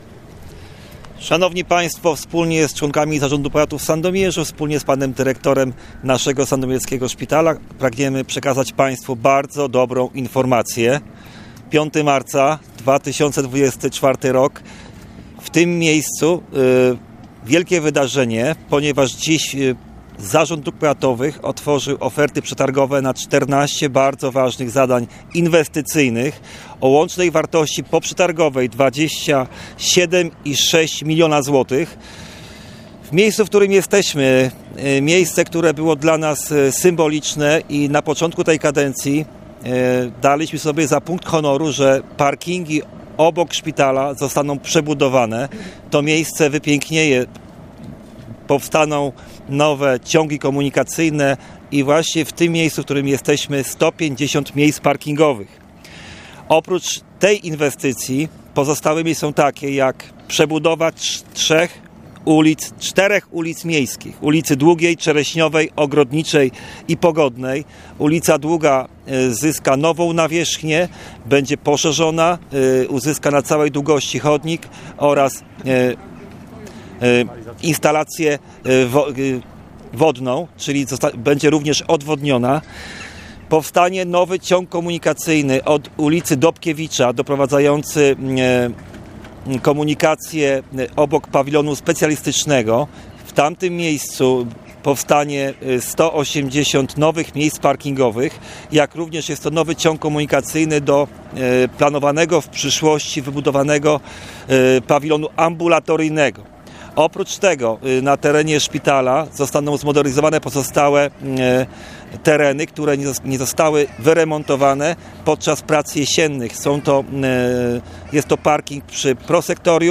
Powiat sandomierski wyłonił wykonawców na czternaście ważnych inwestycji drogowych o wartości w sumie prawie 28 milionów złotych. O szczegółach mówili dziś na konferencji zorganizowanej pod budynkiem szpitala starosta Marcin Piwnik